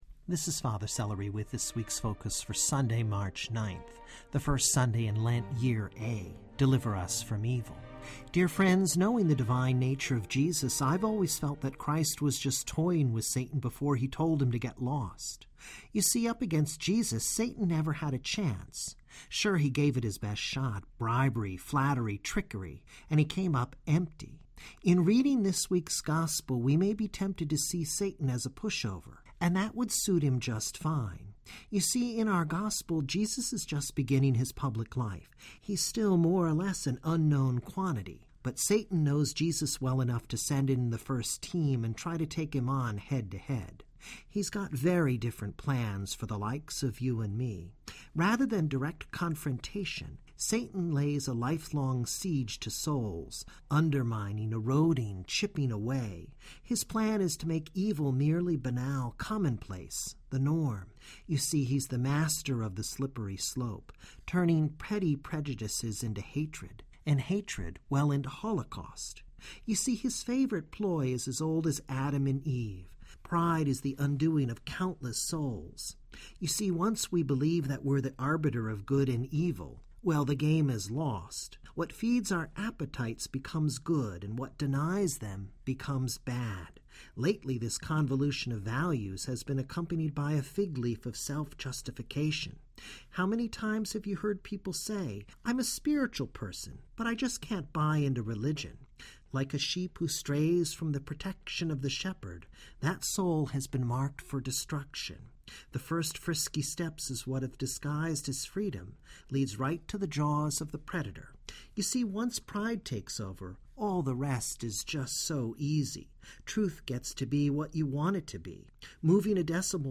Brief Reflection "Deliver us from Evil" on Matthew 4:1-11; First Sunday in Lent, Year A